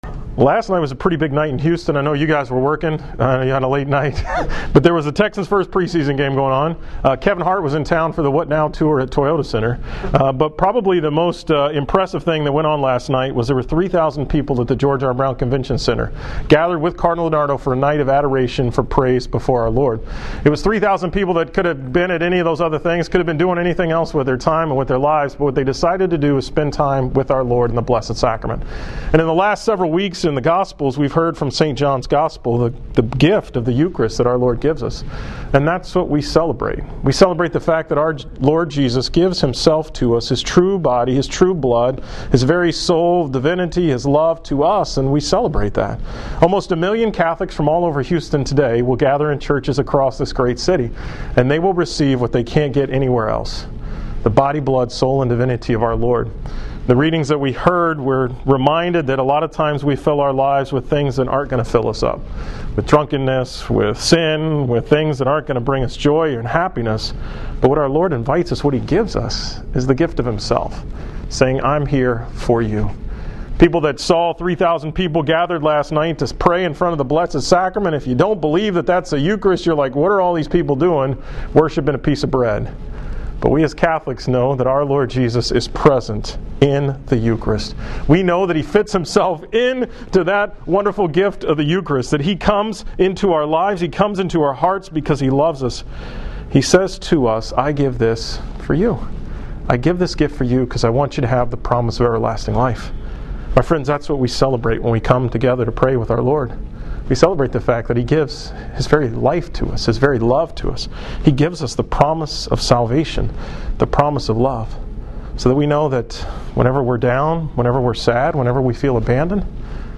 From the 10 am Mass at Minute Maid Park